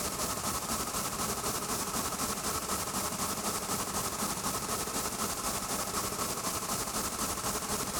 STK_MovingNoiseB-120_02.wav